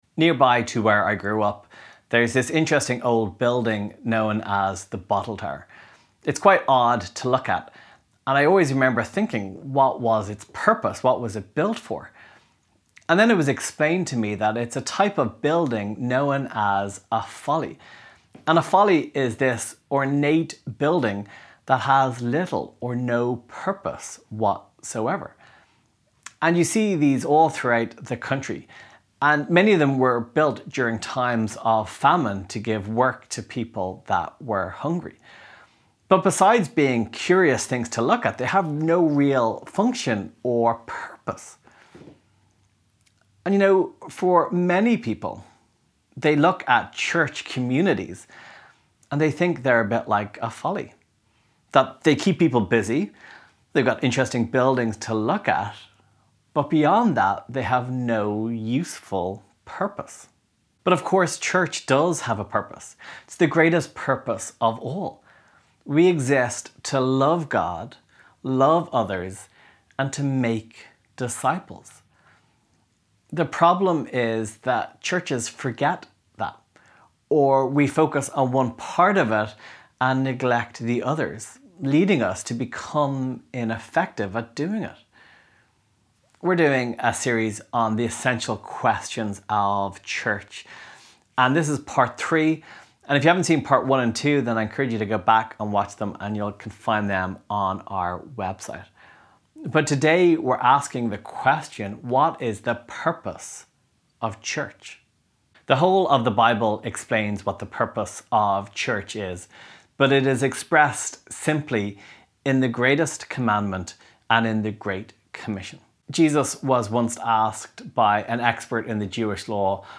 Sermon MP3 1 Corinthians Reading Plan Share this: Share on X (Opens in new window) X Share on Facebook (Opens in new window) Facebook Like Loading...